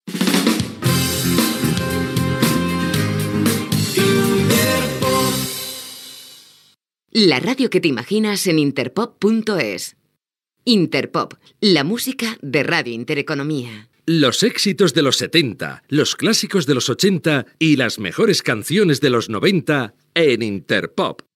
Indicatiu i identificació de l'emissora.